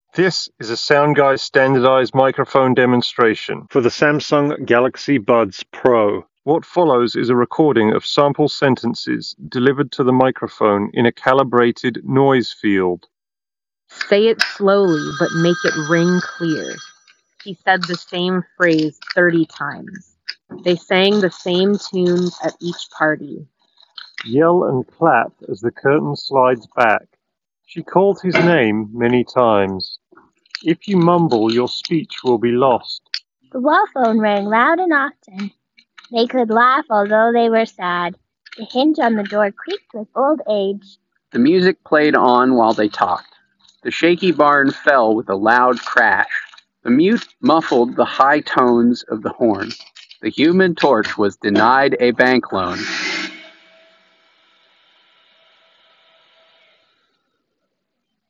Samsung-Galaxy-Buds-Pro_Office-microphone-sample.mp3